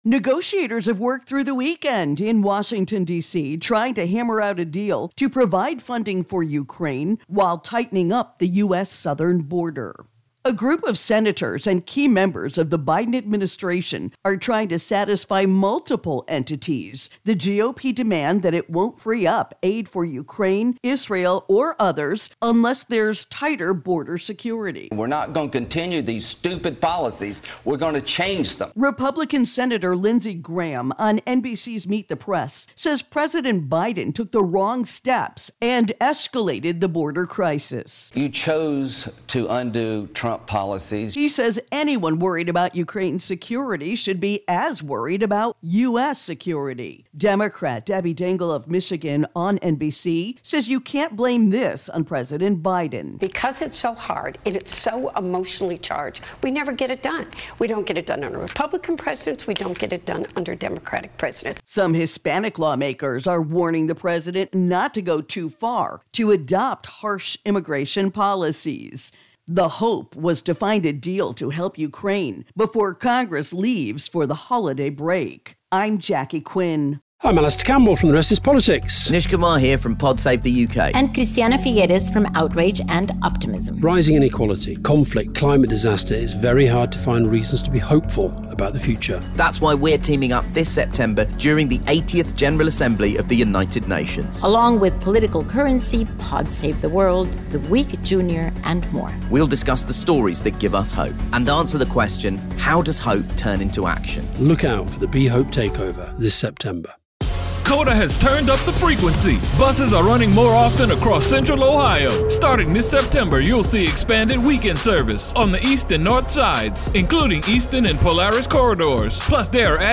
reports on Congress Border Ukraine Funds.